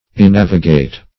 Search Result for " enavigate" : The Collaborative International Dictionary of English v.0.48: Enavigate \E*nav"i*gate\, v. t. [L. enavigatus, p. p. of enavigare.] To sail away or over.